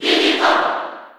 File:Diddy Kong Cheer JP SSB4.ogg
Diddy_Kong_Cheer_JP_SSB4.ogg.mp3